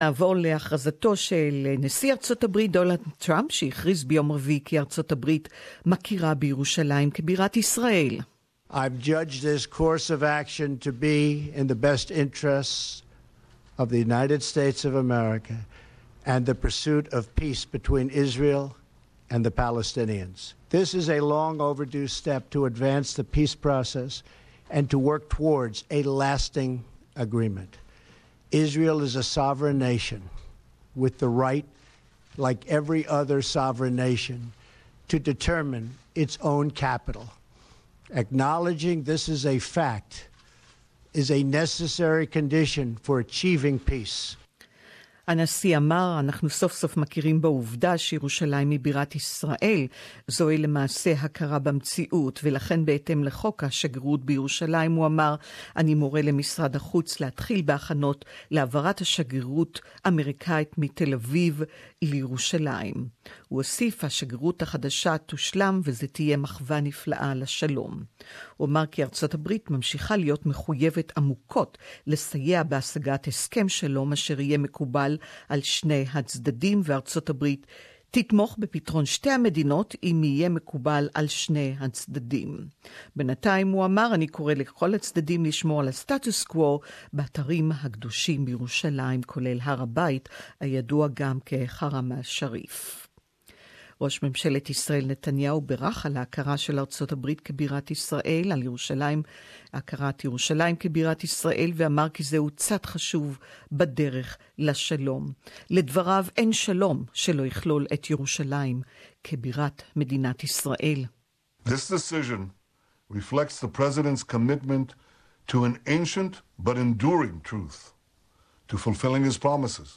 US President Donald Trump declared last week that his country recognizes Jerusalem as the capital of Israel. comprehensive feature/news report